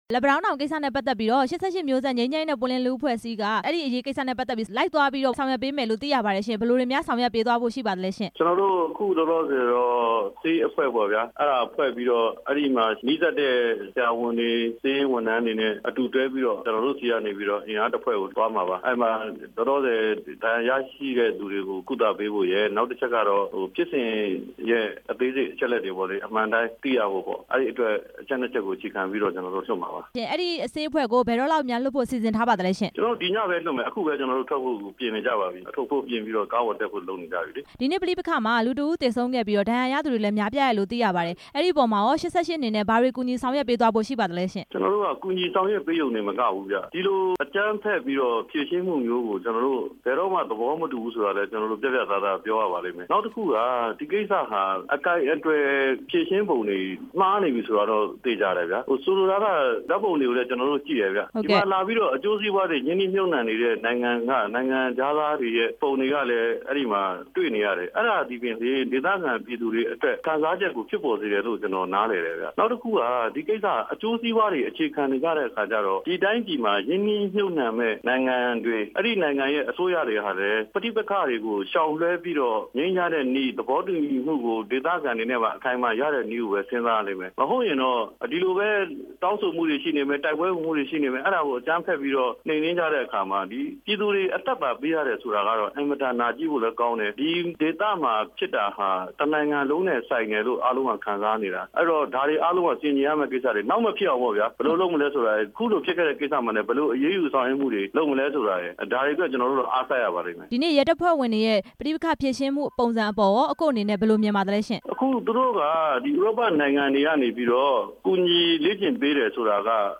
လက်ပံတောင်းတောင် ဒေသခံတွေကို ဆေးအဖွဲ့လွှတ်ပြီး ကူညီမယ့်အကြောင်း မေးမြန်းချက်